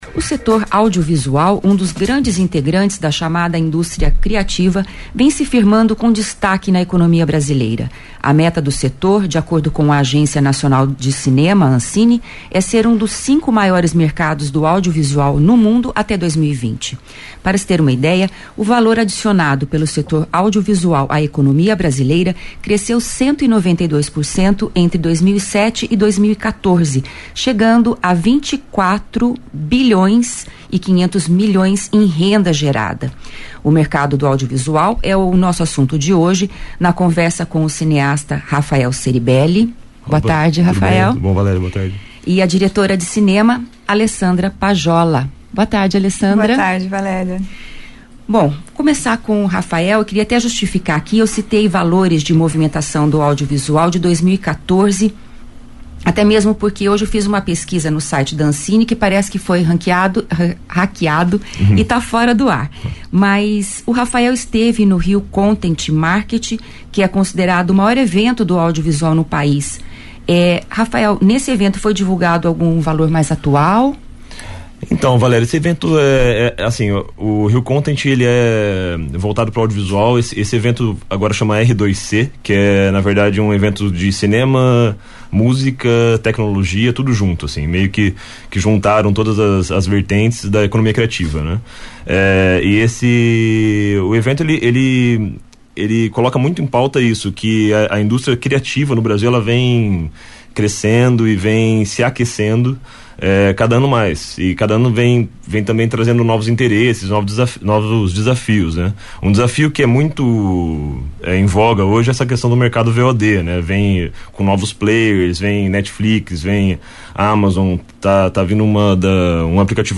Entrevista UEL FM